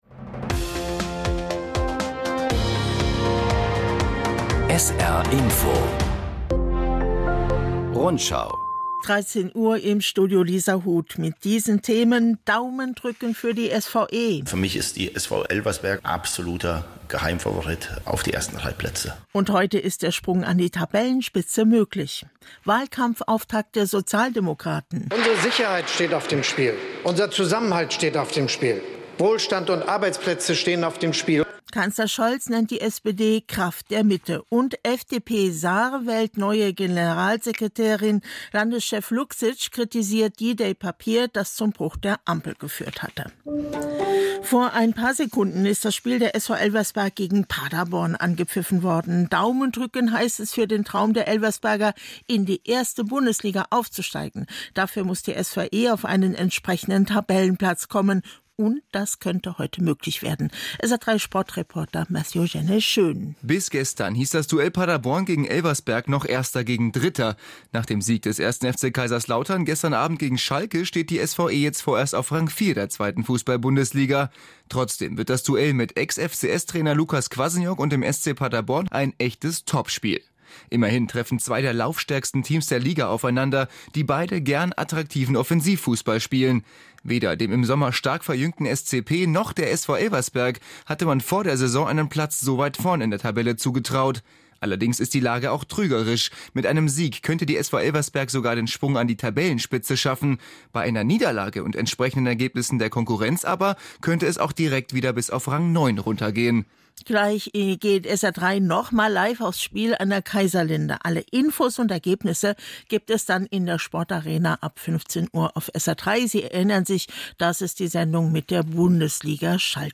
… continue reading 4 епізоди # Nachrichten